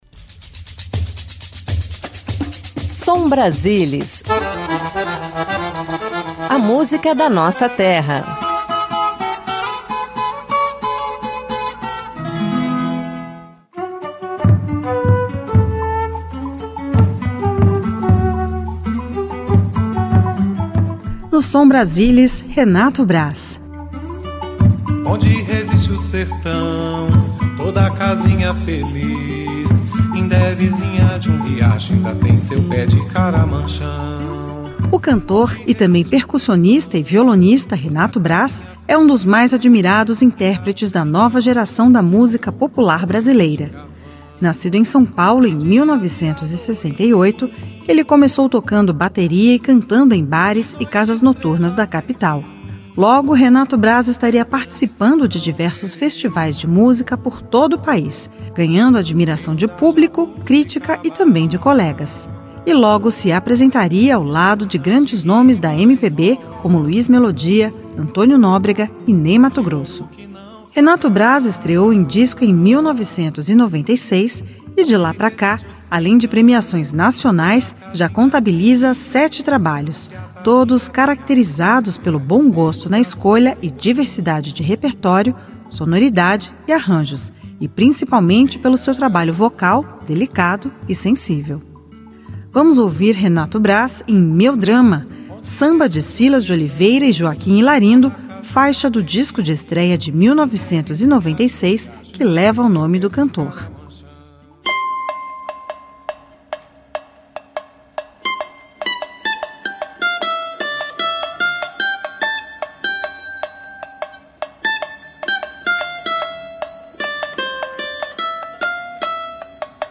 Música Brasileira MPB